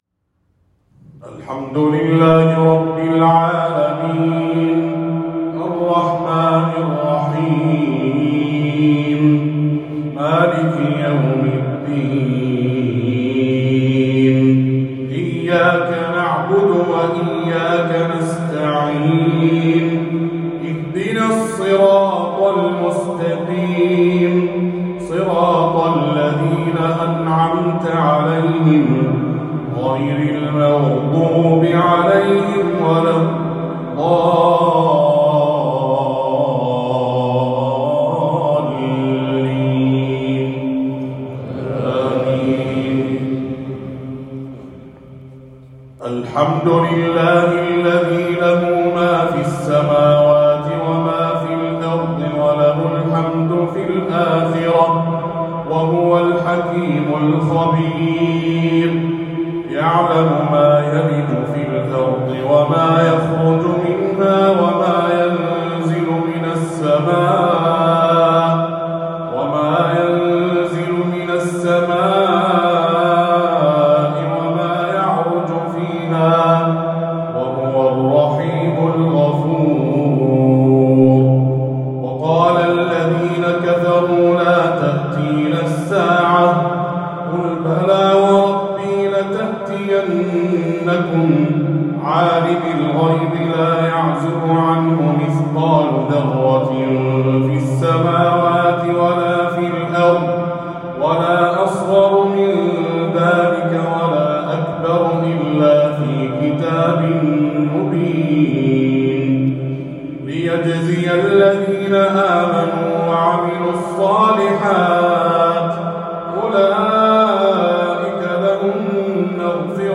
تلاوة من سورة سبأ